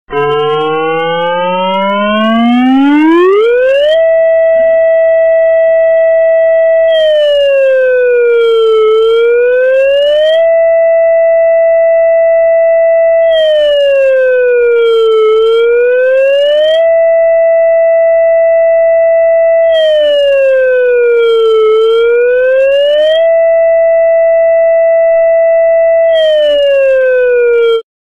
Turkey Eas Alarm Real Red Alert